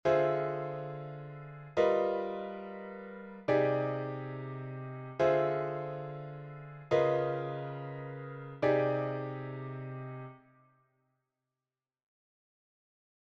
Improvisation Piano Jazz
Substitution Tritonique
La conséquence de cette substitution est une progression de la basse par demi-tons .